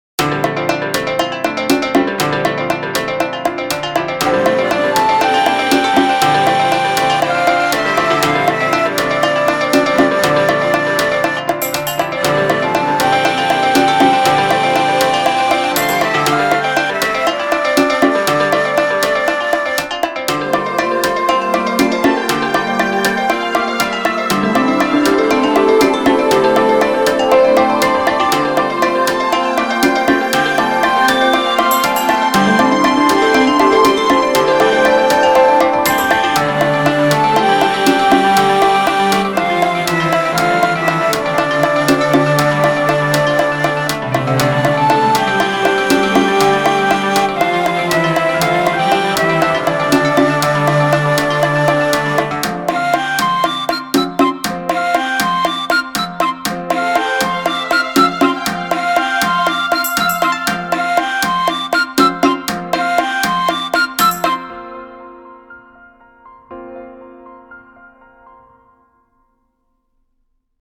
【1.35MB　1:10】イメージ：エスニック、涼しげ、笛、弦楽器